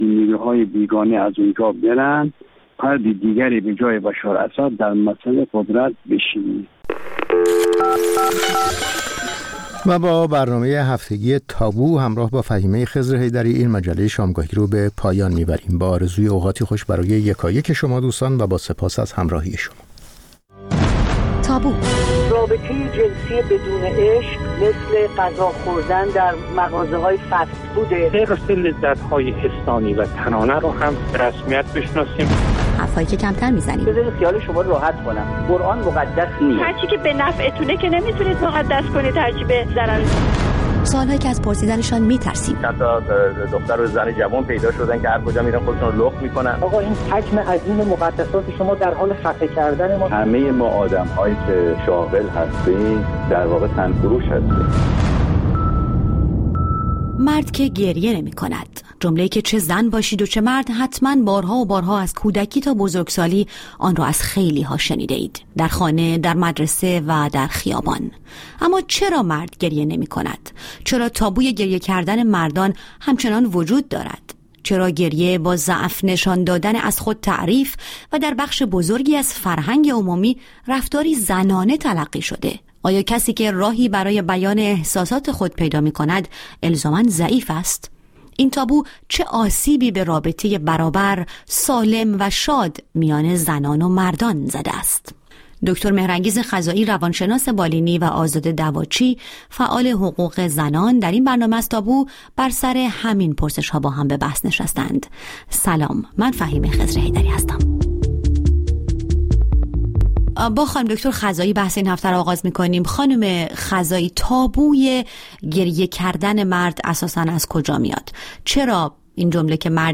تابو را هر هفته پنج‌شنبه‌ها ساعت هفت‌ونیم بعدازظهر به وقت ایران از رادیوفردا بشنوید.